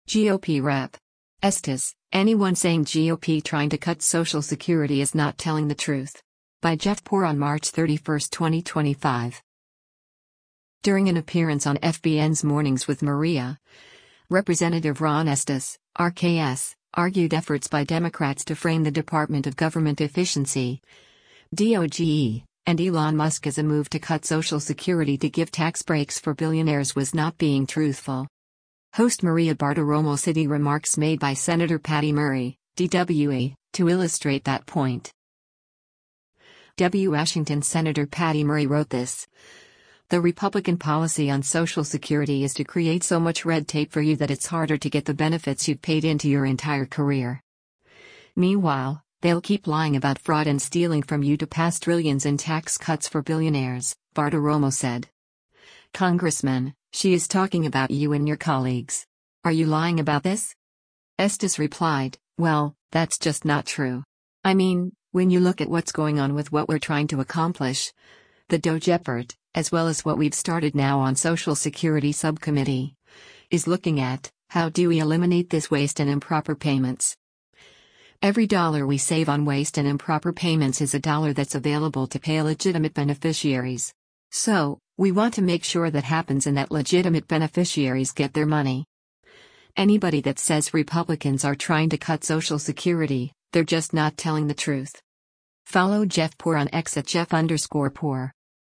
During an appearance on FBN’s “Mornings with Maria,” Rep. Ron Estes (R-KS) argued efforts by Democrats to frame the Department of Government Efficiency (DOGE) and Elon Musk as a move to cut Social Security to give tax breaks for “billionaires” was not being truthful.